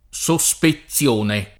SoSpeZZL1ne], suspezione [